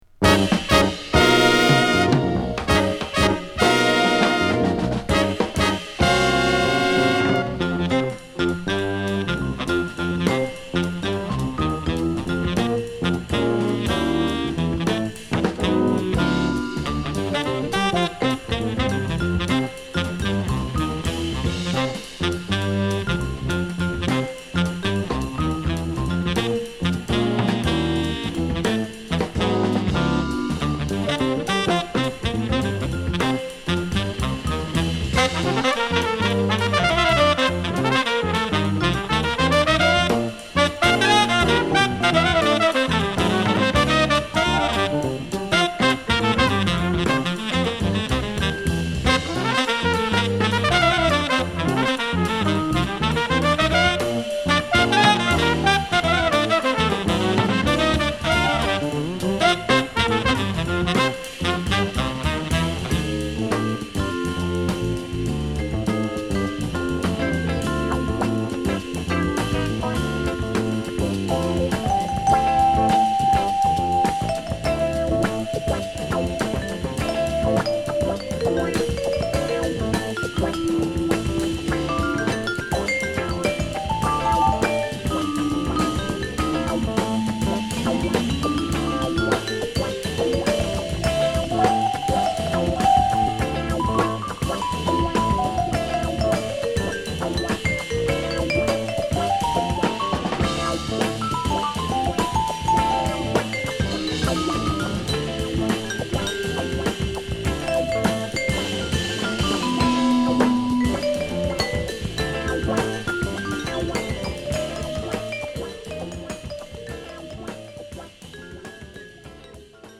ノースキャロライナ出身のSax奏者